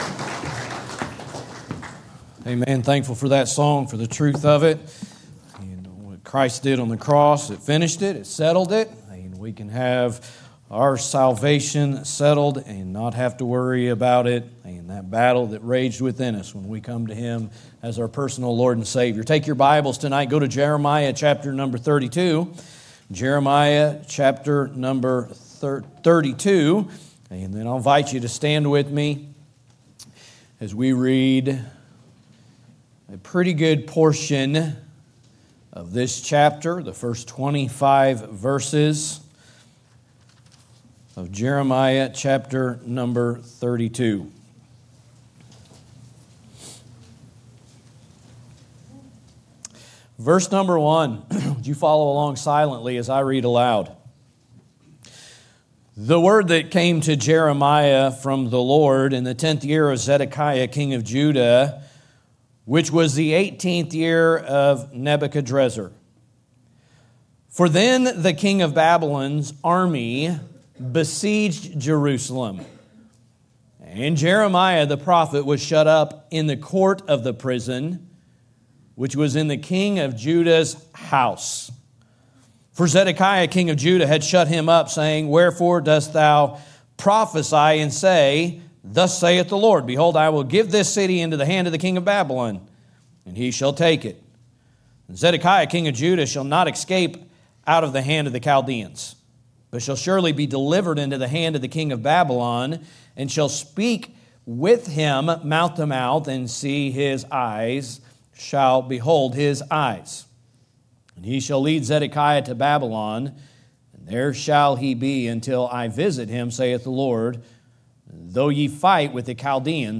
preaching through Jeremiah & Lamentations. Scripture References: Jeremiah 32:1-25